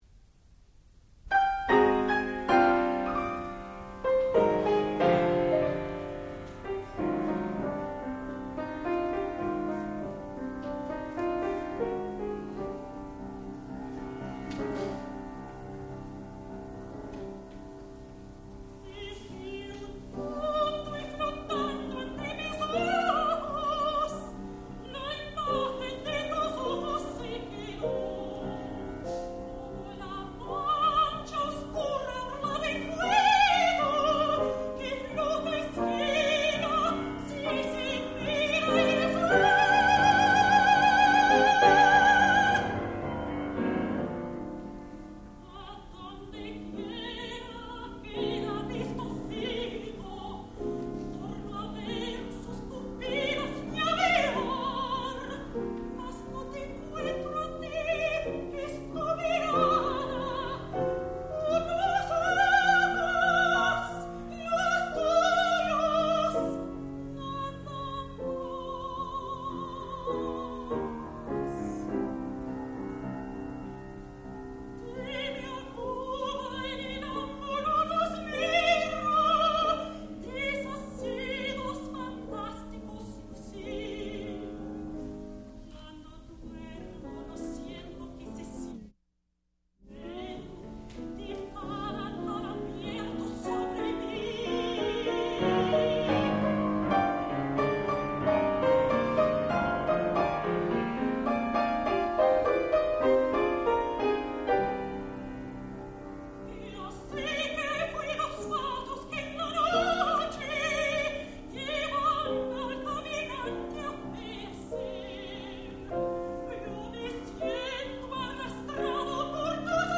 Rima (aria for voice, piano)
classical music